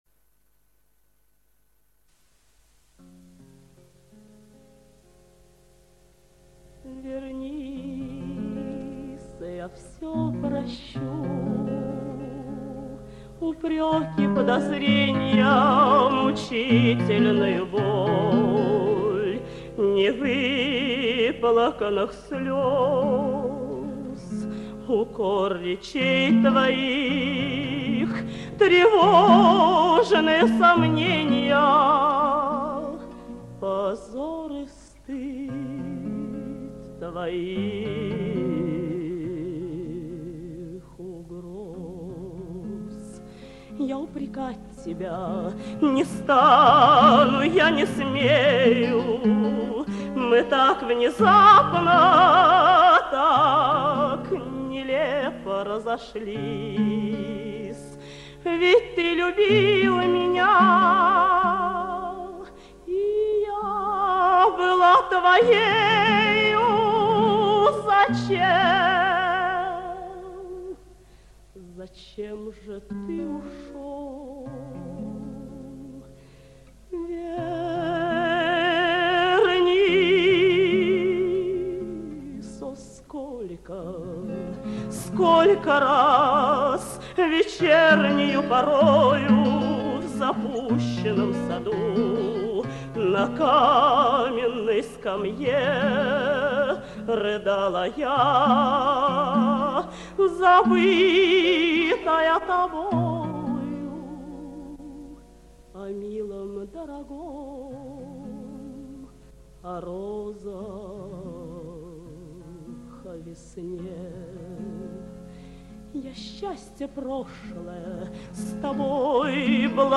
Это русский романс, не цыганский...